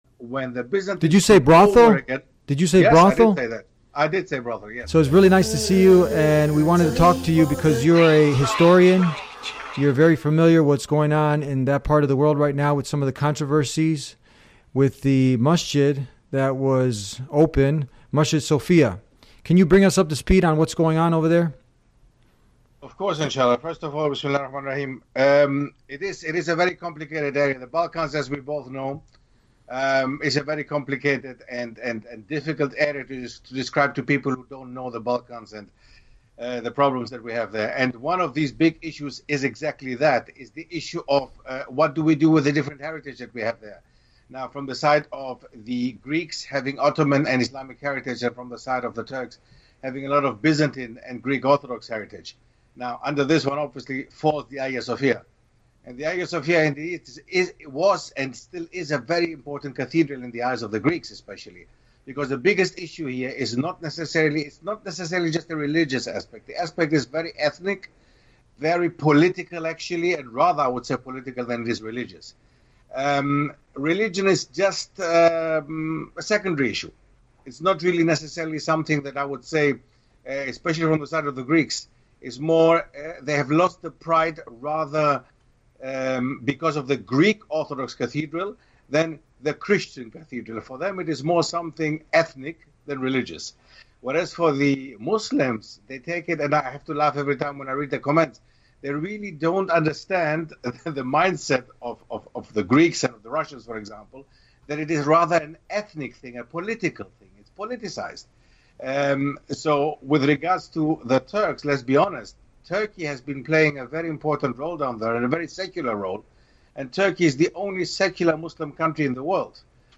The guest, a historian, highlights the intertwined political, ethnic, and religious aspects of the region, particularly the Balkans, where differing heritages have led to tensions and misunderstandings. The conversion of the cathedral into a mosque symbolizes more than just a religious shift; it reflects deeper struggles of identity, pride, and histor